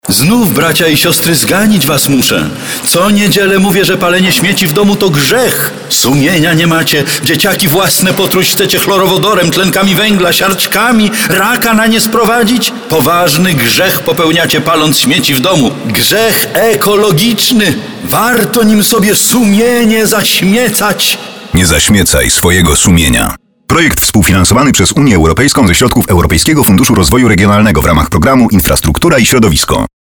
Na kampanię medialną, póki co, składają się 3 spoty filmowe (w dwóch wersjach 30 i 15 sekundowej) oraz trzy spoty radiowe.